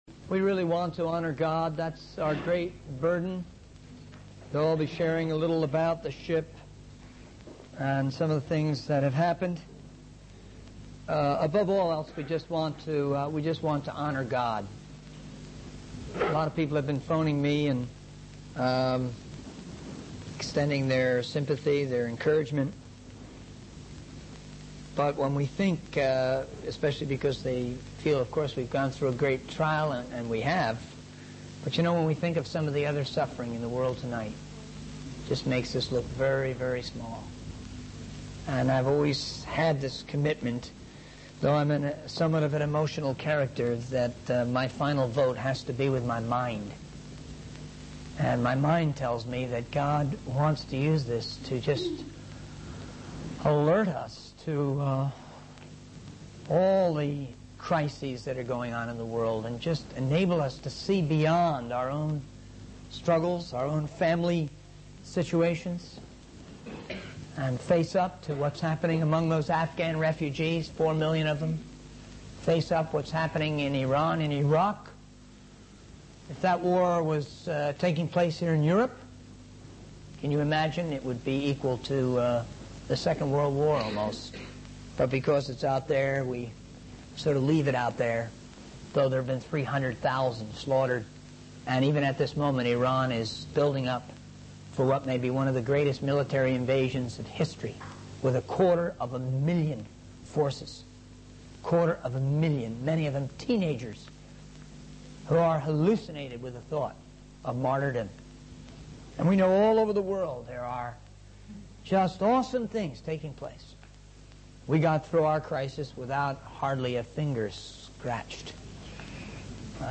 In this sermon, the speaker reflects on a news event that occurred in the Beagle Channel.